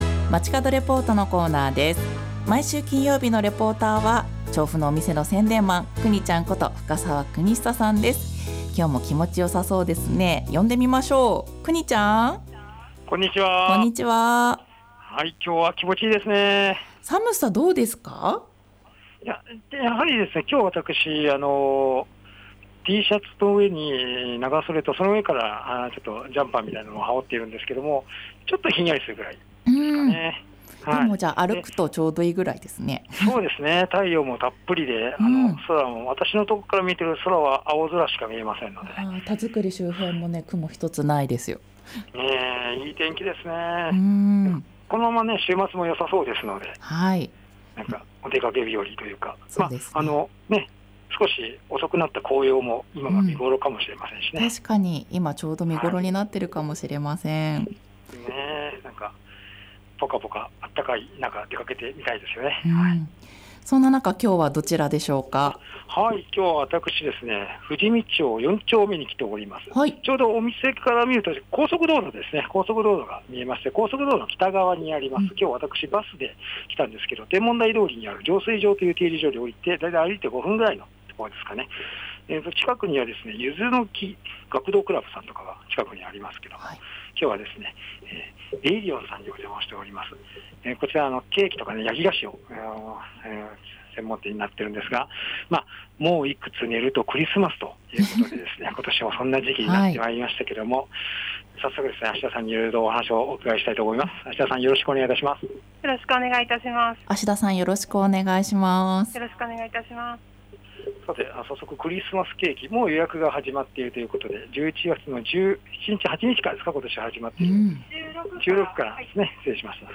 街角レポート